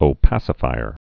(ō-păsə-fīər)